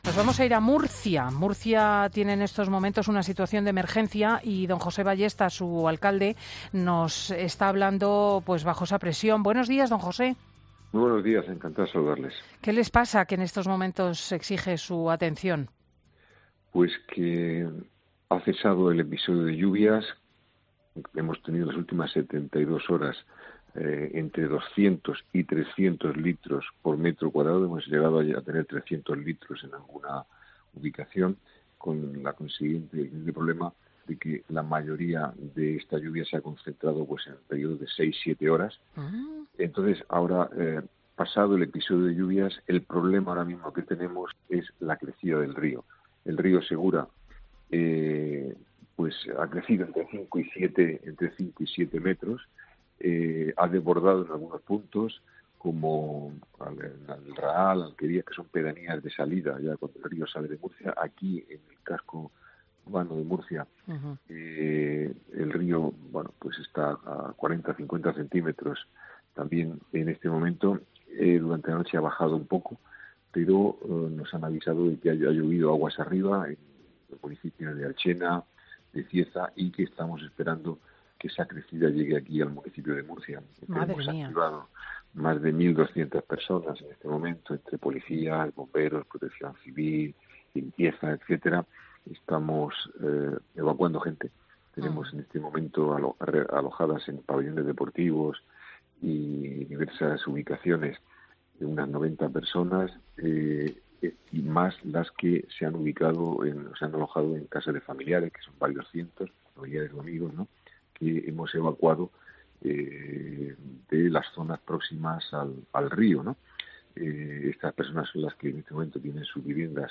Los alcaldes Orihuela y Murcia en 'Fin de Semana'